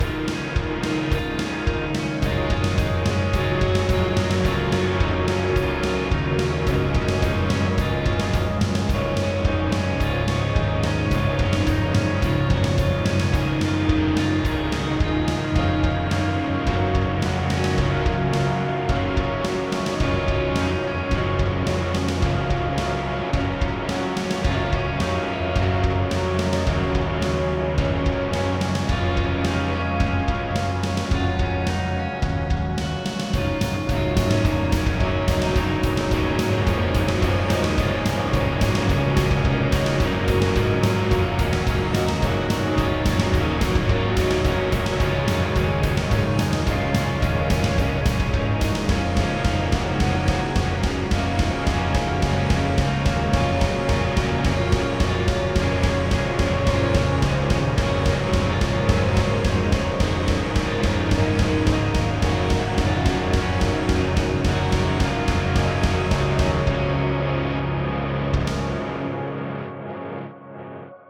An improvised shoegaze song I'm submitting for the 2025 Express Yourself Music Contest.
shoegaze
Music / Rock